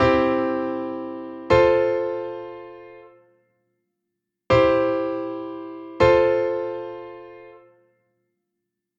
This example added tension and made the C chord not just a stable C chord, but made it want to move to F. This is because C first inversion had the E in the bass, and it wants to move up to the F. It also made our voice leading smoother instead of jumping multiple steps to get to the next note.
Root-to-Inversion-C.mp3